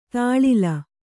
♪ tāḷila